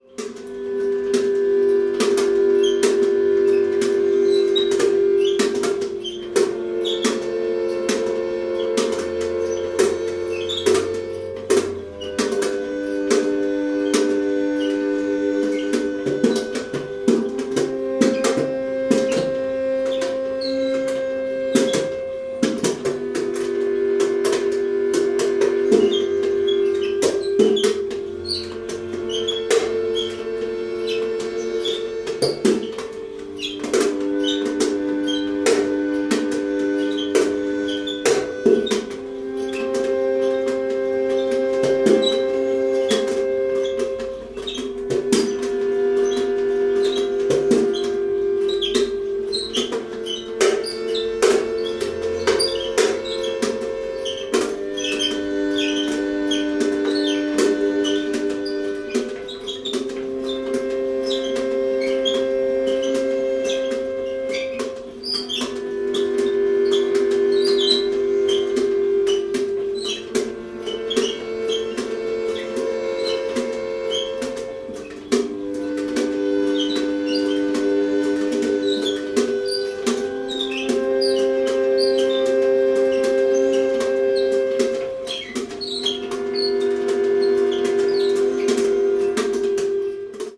声は使わず、オルゴール、ターンテーブル、そして7つの発振器を内蔵するシドラッシ・オルガンを用いている。
まるでメビウスの輪の表面をなぞるようにドリームとナイトメアが次々に入れ替わって行く。